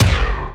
VEC3 Percussion
VEC3 Percussion 043.wav